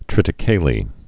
(trĭtĭ-kālē)